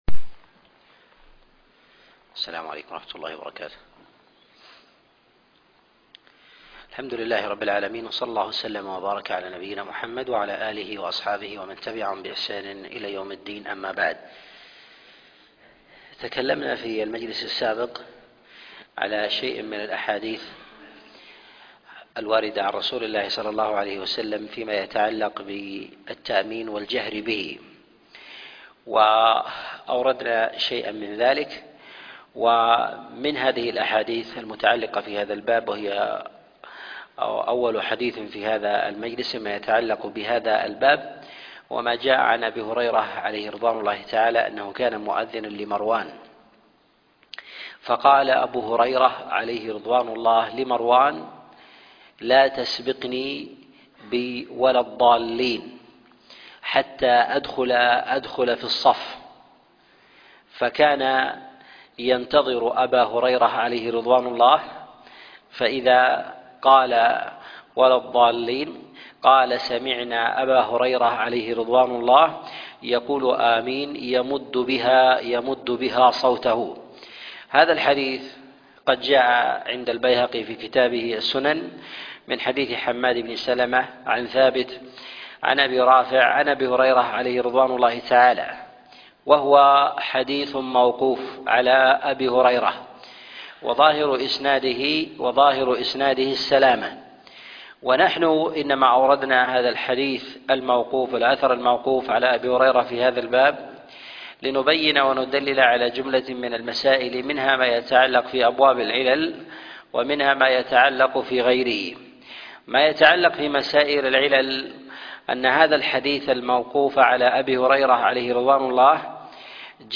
الأحاديث المعلة في الصلاة الدرس 38